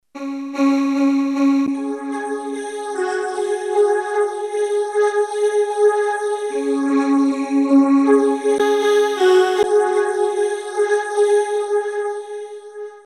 edit 1- RSS is a 3 dimensional audio field process that "moves" a sound on the the 3-axes dimension (left-right + above-below).
RSS 3D effect